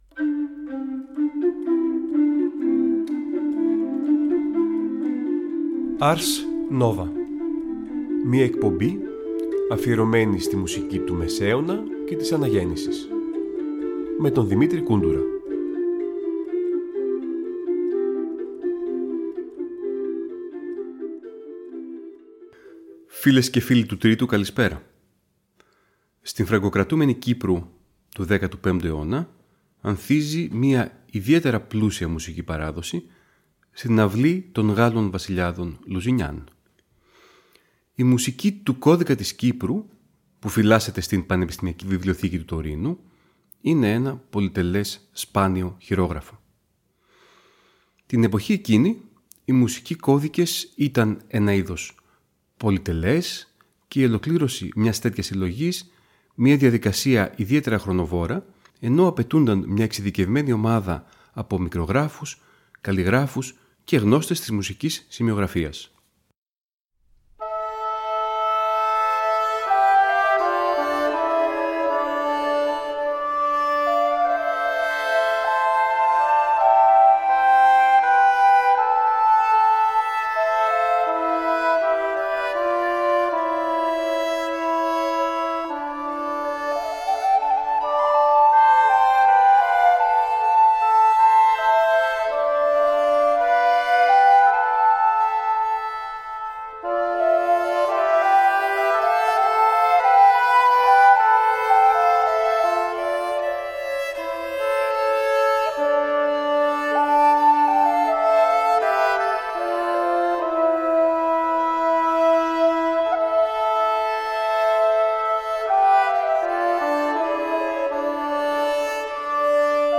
Νέα ωριαία μουσική εκπομπή του Τρίτου Προγράμματος που μεταδίδεται κάθε Τρίτη στις 19:00.